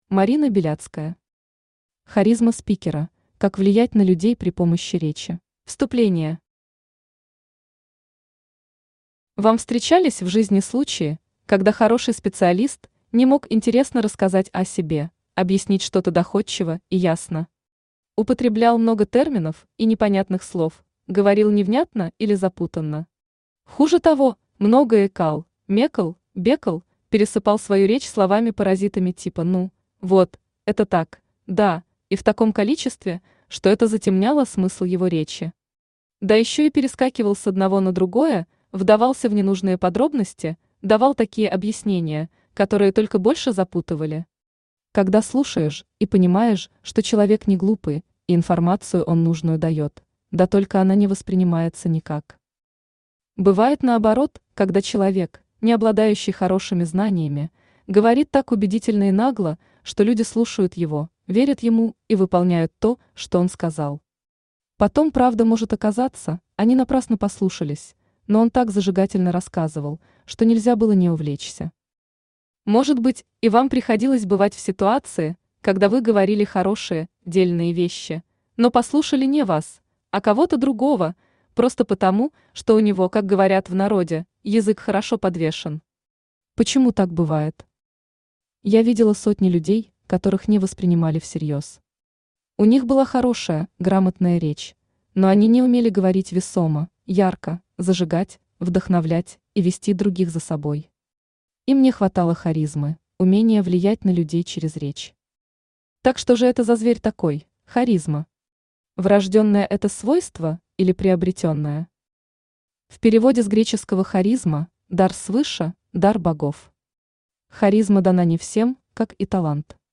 Aудиокнига Харизма спикера: как влиять на людей при помощи речи Автор Марина Беляцкая Читает аудиокнигу Авточтец ЛитРес.